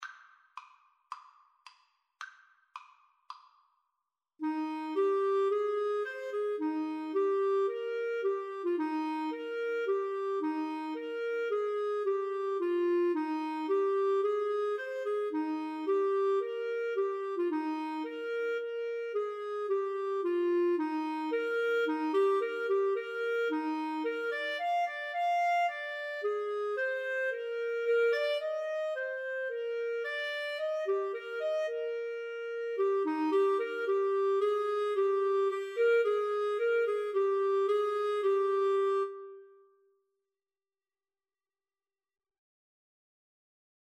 Free Sheet music for Flute-Clarinet Duet
Traditional Music of unknown author.
Eb major (Sounding Pitch) F major (Clarinet in Bb) (View more Eb major Music for Flute-Clarinet Duet )
Allegro Moderato = c.110 (View more music marked Allegro)
4/4 (View more 4/4 Music)